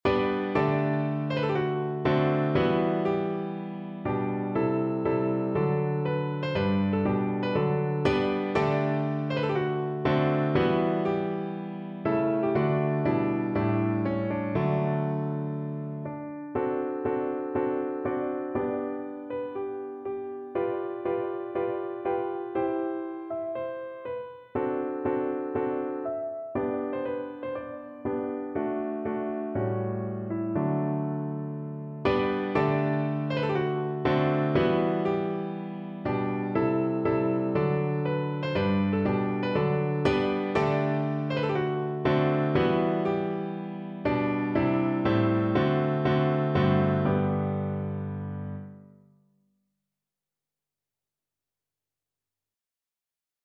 Piano version
No parts available for this pieces as it is for solo piano.
2/4 (View more 2/4 Music)
Piano  (View more Intermediate Piano Music)
Classical (View more Classical Piano Music)